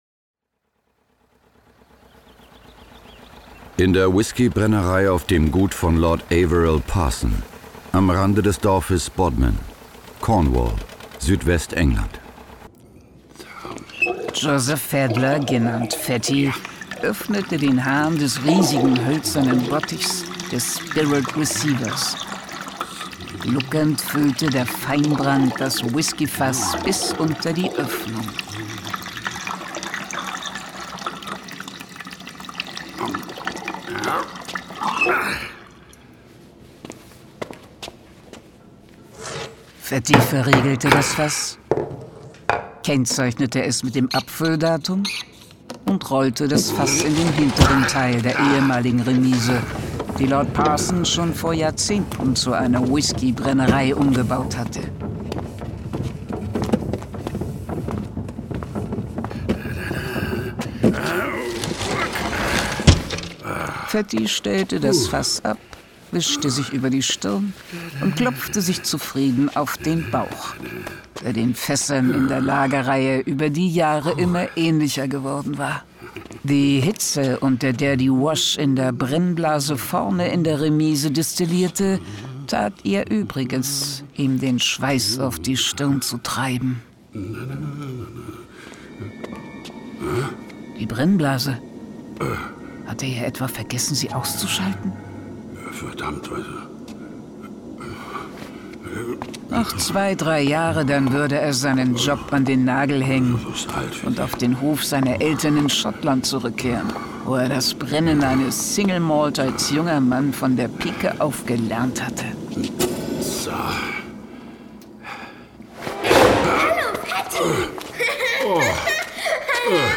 Hörspiel.